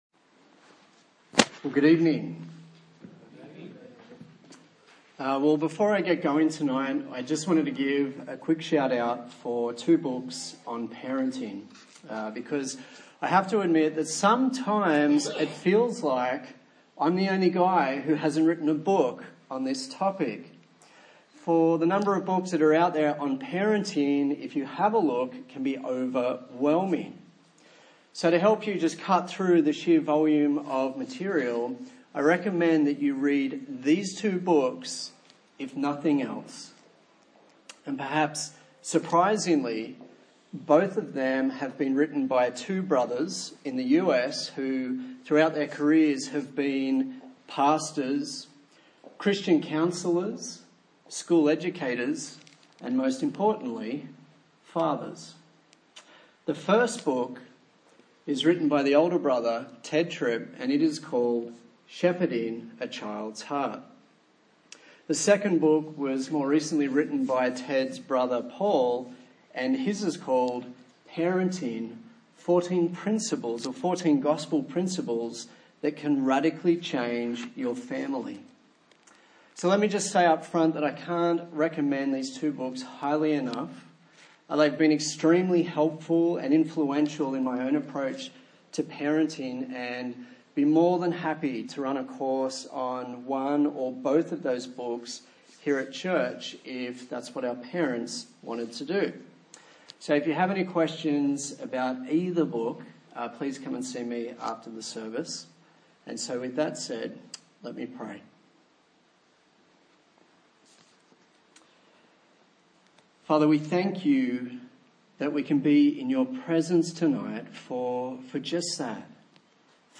A sermon in the series on Parenting - Honour & Obey